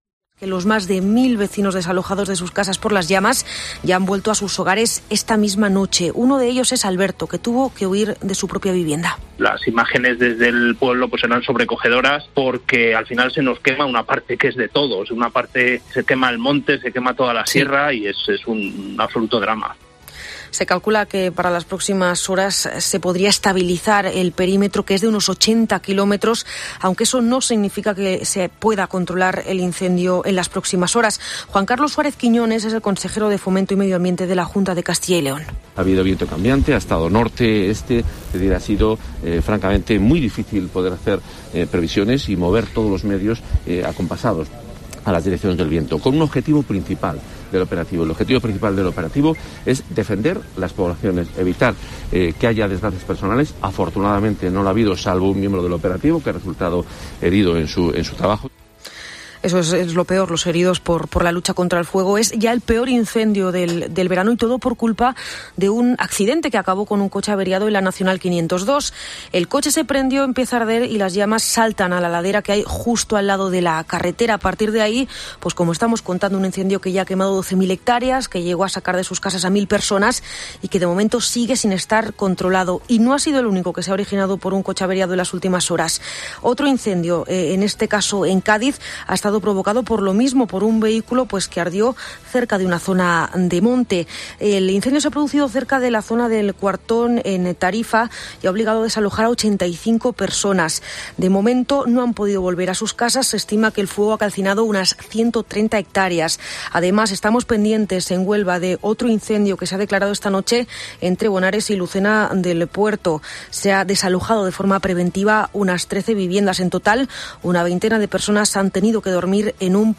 Informativo Matinal Herrera en COPE Ávila -17-agosto